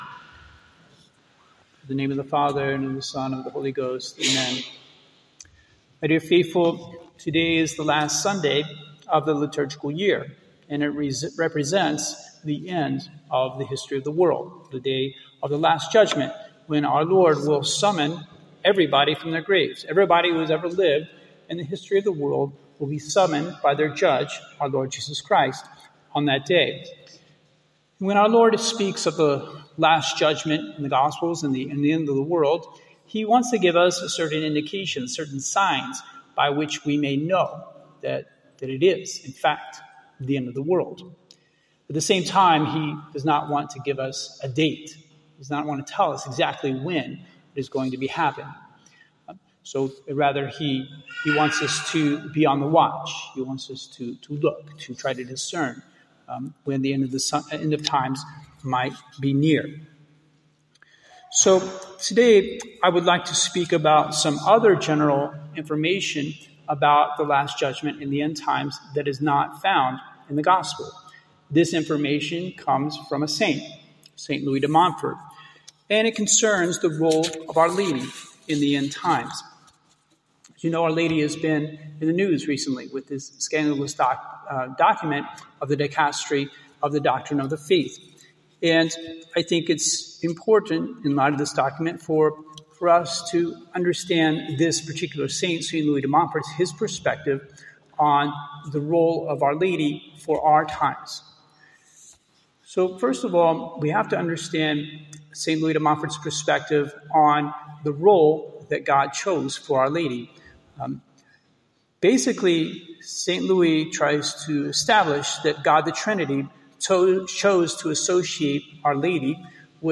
Our Lady and the End Times, Sermon